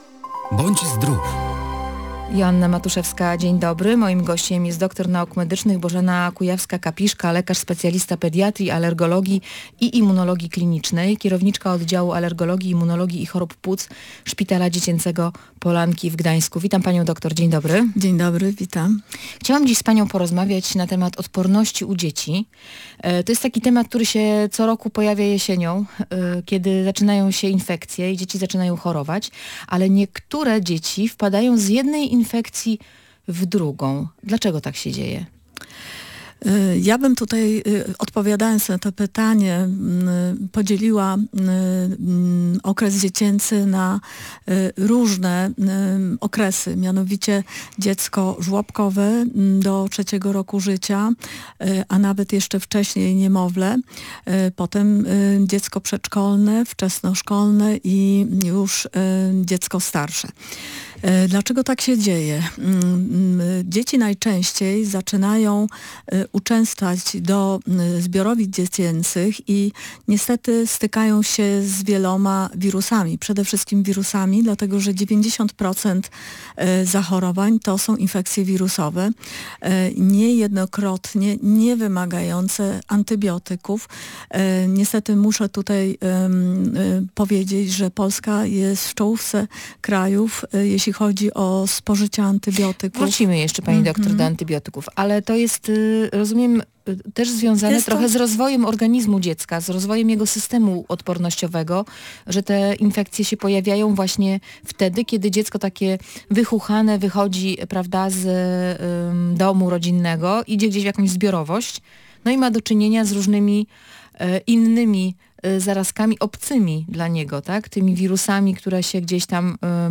Dzieci budują odporność przez kilka pierwszych lat życia. Potrzebują witamin, w tym D3 oraz żelaza- mówiła w audycji medycznej dr n. med.